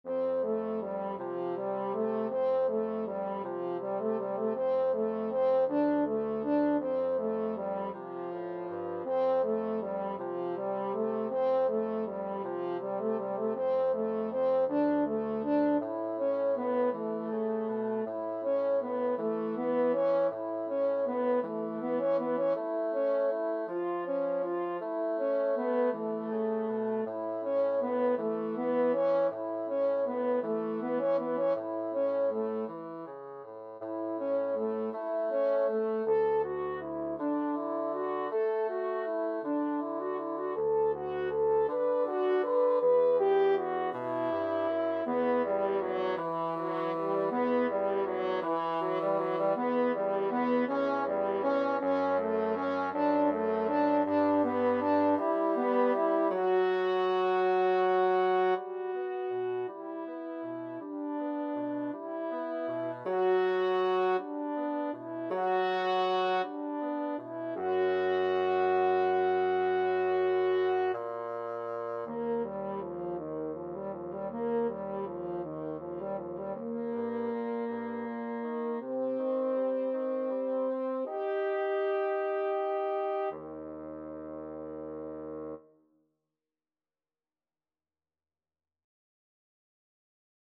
Andante
6/8 (View more 6/8 Music)